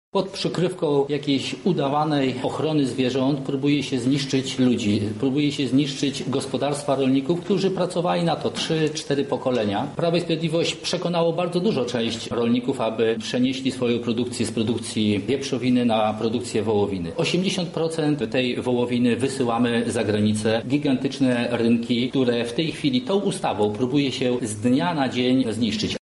Ta ustawa to bomba atomowa zrzucona na polską wieś.- mówi poseł Kukiz’15 Jarosław Sachajko: